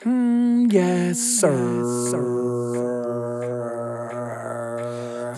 Hmm Yessir.wav